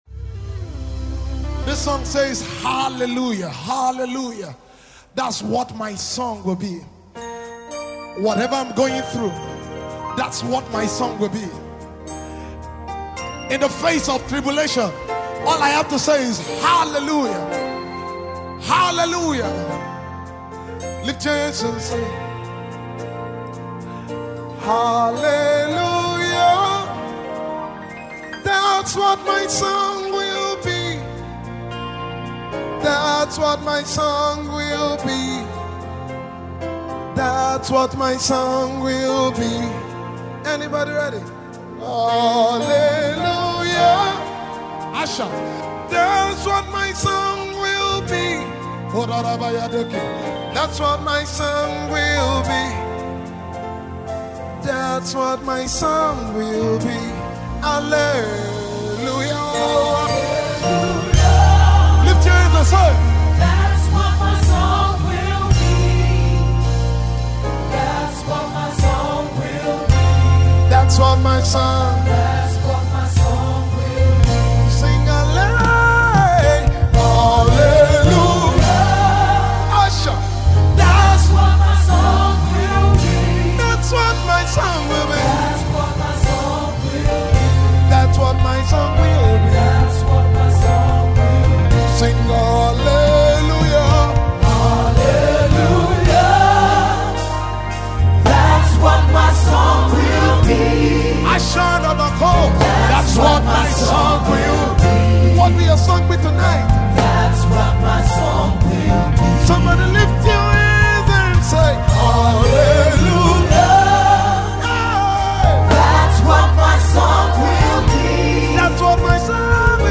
simple worship piece
recorded live